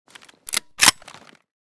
kiparis_unjam.ogg